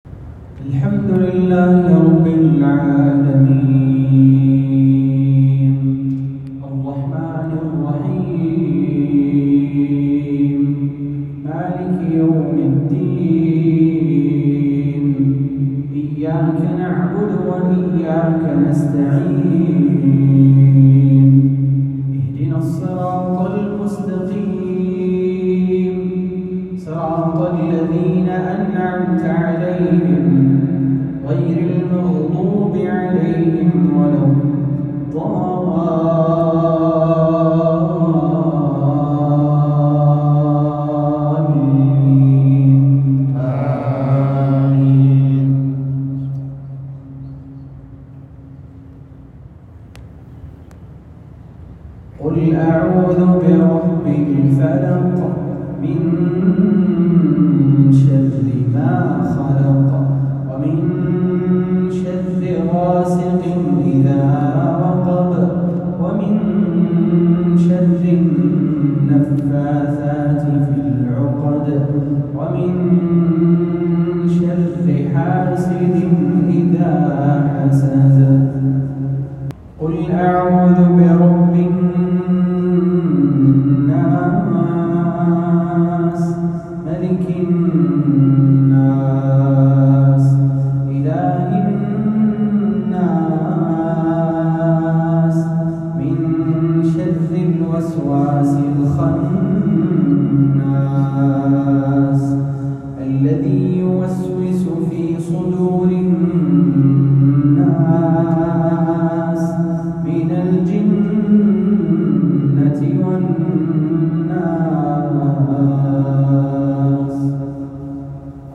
الأحد ٤ محرم ١٤٤٧هـ | بجامع الإيمان حي شوران بالمدينة النّبوية.